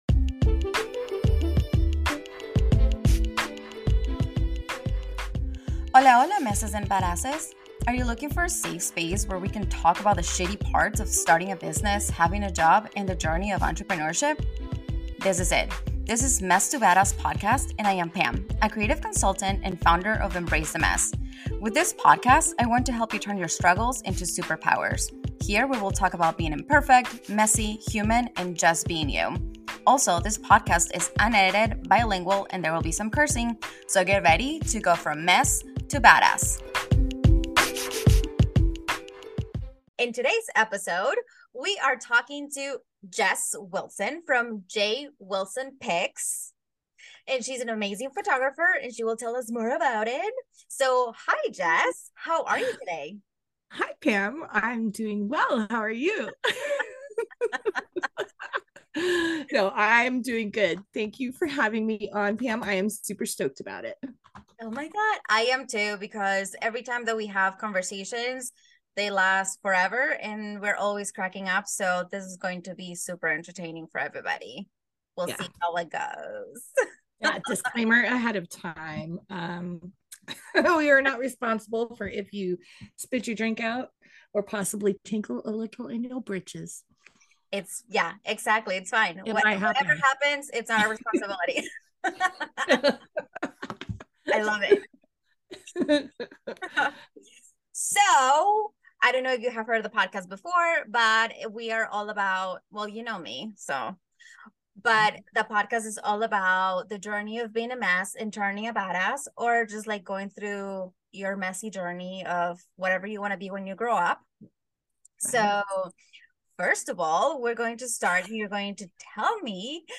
There are going to be tons of laughs and giggles.